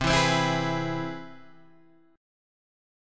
C Augmented Major 7th